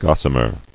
[gos·sa·mer]